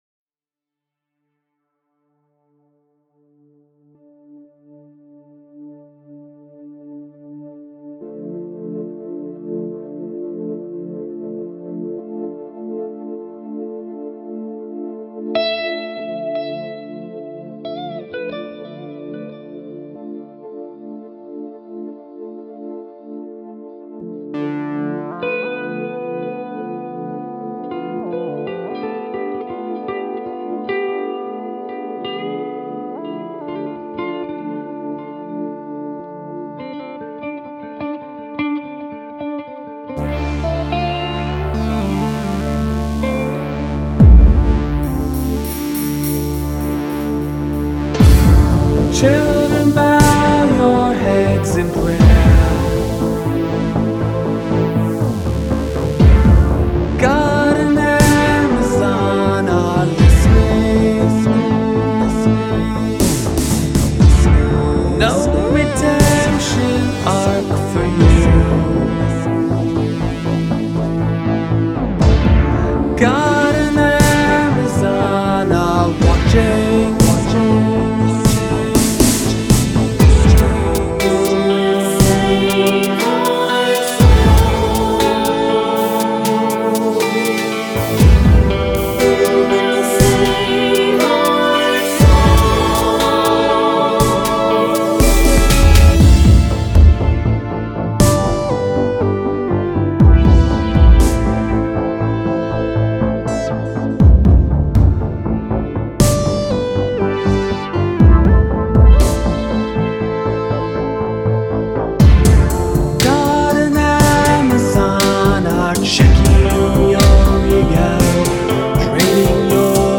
additional vocals